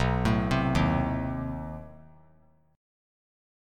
Listen to B7b9 strummed